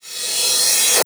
VEH1 Reverse Cymbal - 10.wav